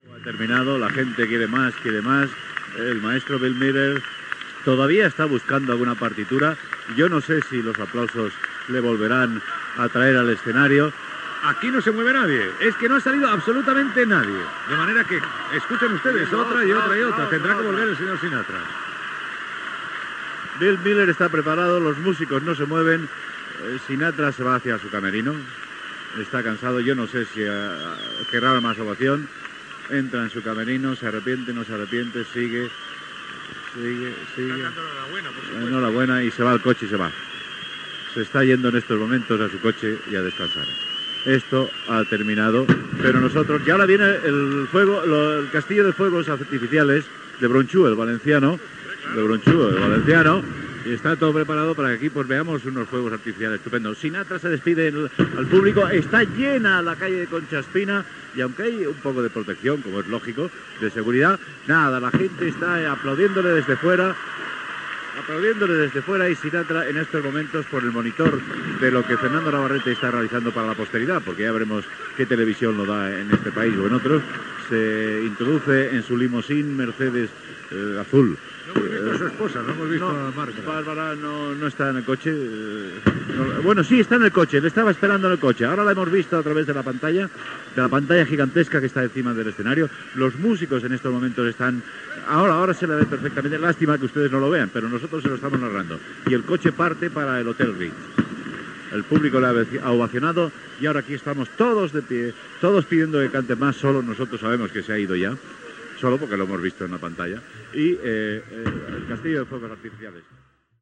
Transmissió de l'actuació de Frank Sinatra a l'Estadio Santiago Bernabeu de Madrid,
Narració del final del concert i de la marxa del cantant de l'estadi en cotxe.